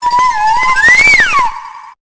Cri de Givrali dans Pokémon Épée et Bouclier.